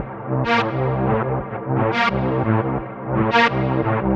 Convoy Strings 02.wav